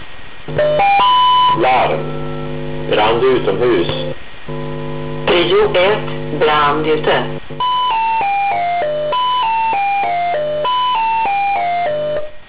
Larm!
Inte själva radiokommunikationen mellan enheter och larmcentral, den kommer senare, utan hur det låter på stationen när det är dags att släppa allt och springa till bilen.
Klicka på nedanstående två länkar för att lyssna på en "klassisk" larmsignal och en mer futuristisk sådan, med talsyntes!
>>Futuristisk, med talsyntes!